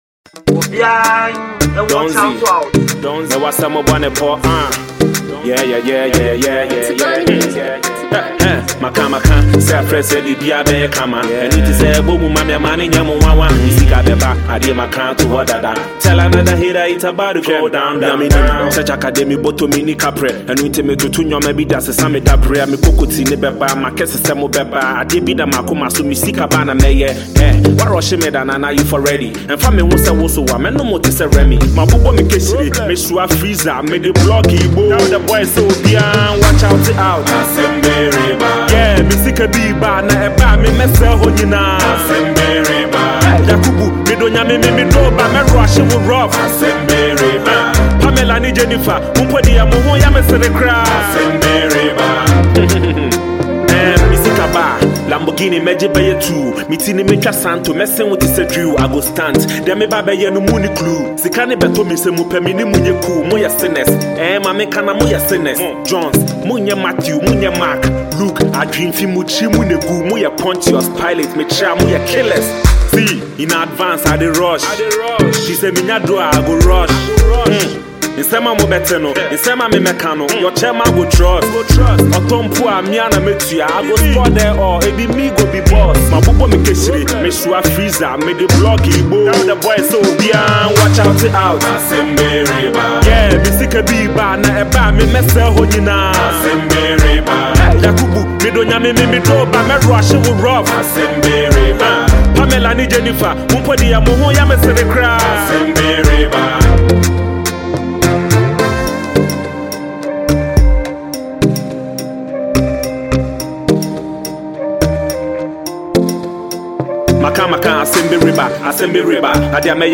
Ghana Music